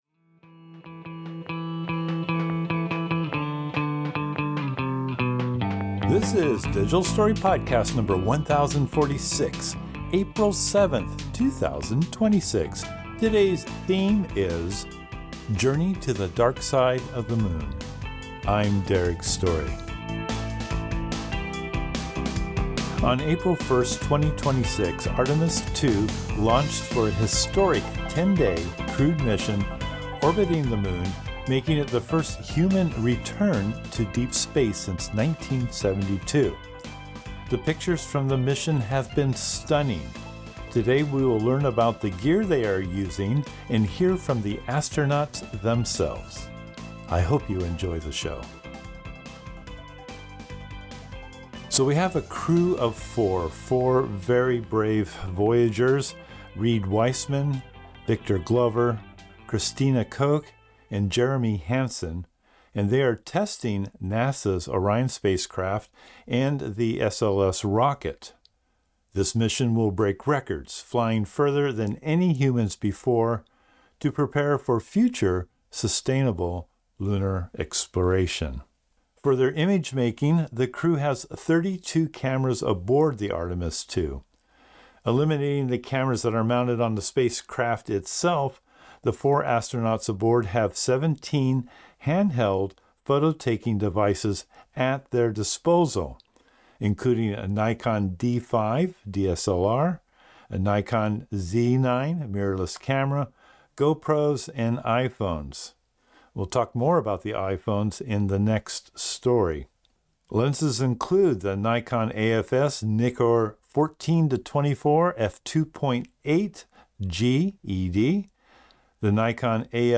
Today we will learn about the gear they are using and hear from the astronauts themselves.
Apple Podcasts -- Spotify Podcasts -- Stitcher Podbean Podcasts -- Podbay FM -- Tune In Journey to the Dark Side of the Moon NASA provided the audio of Mission Control and the astronauts, as well as the image for this podcast.